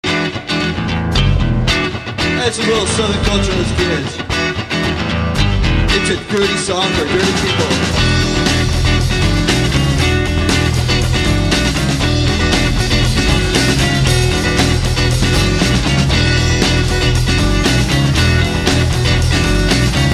he is the bass player".